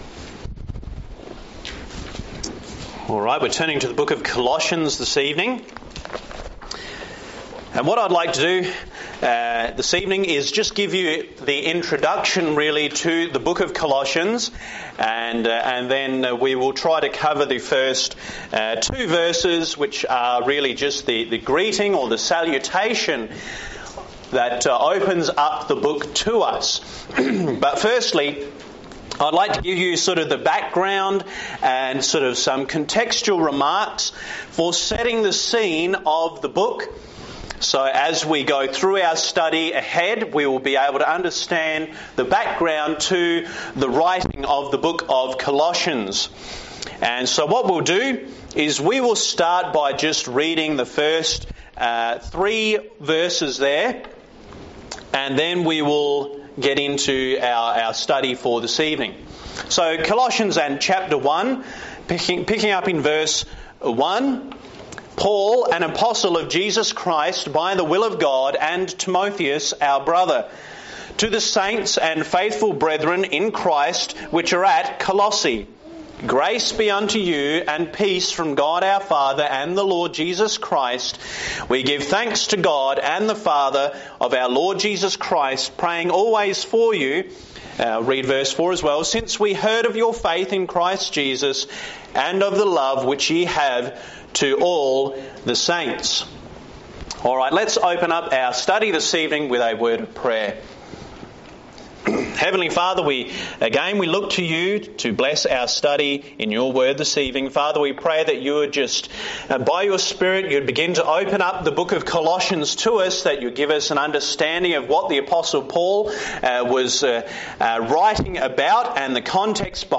This sermon is an introductory message to the book of Colossians. Colossians is one of the Pauline Prison Epistles and is a short letter to a church which was struggling with heresy making it's way into the church.